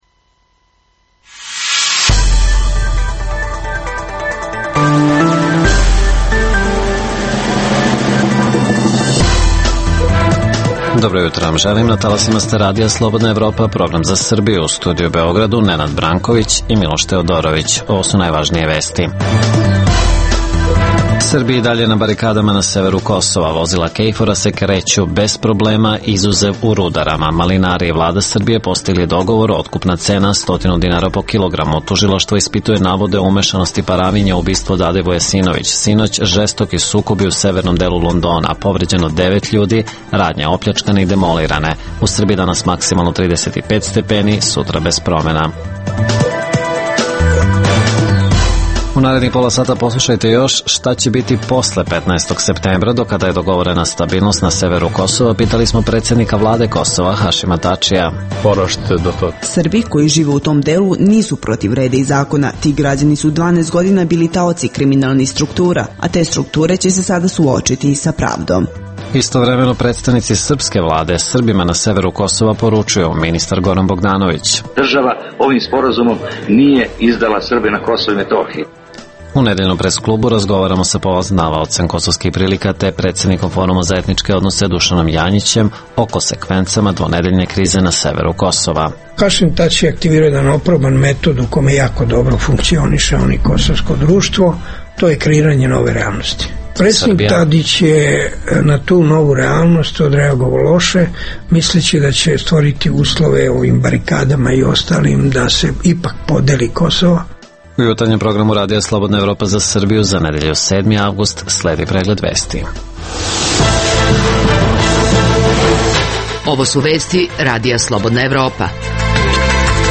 U emisiji možete čuti: - O krizi na severu Kosova za Radio Slobodna Evropa govori premijer Kosova Hašim Tači.